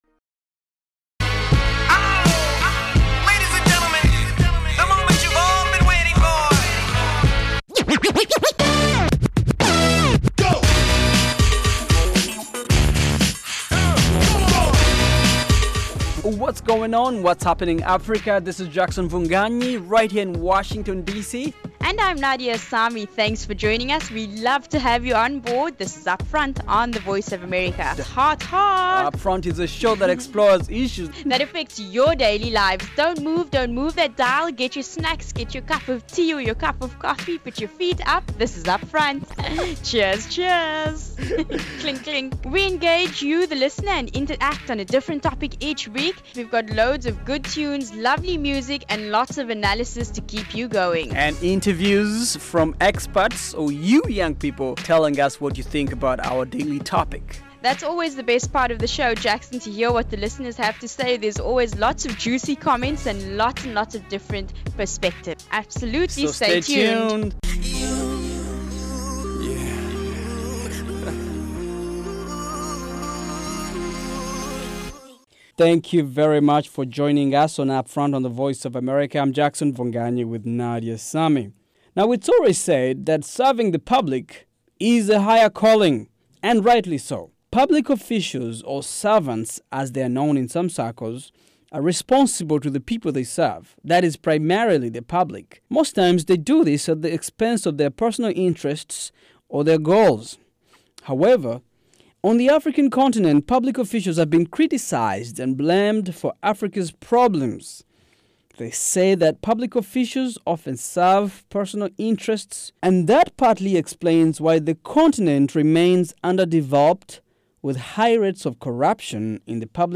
fresh, fast-paced show